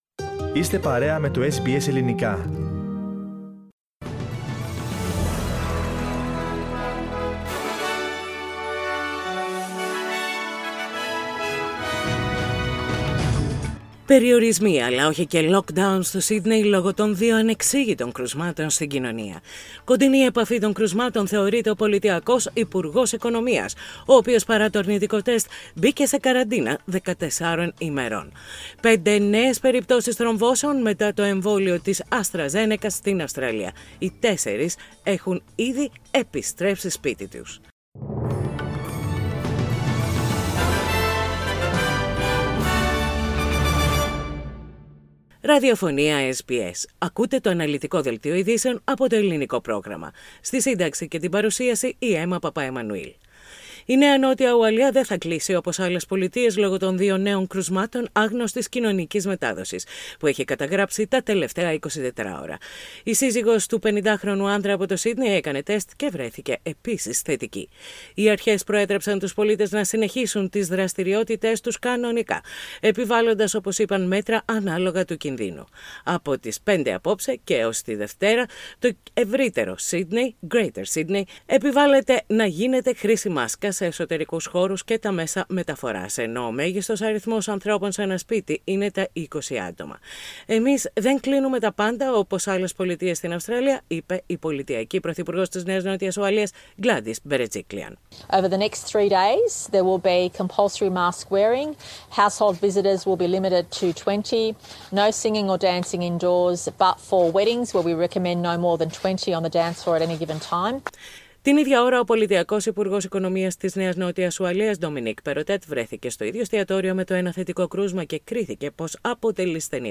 News in Greek - Thursday 6.5.21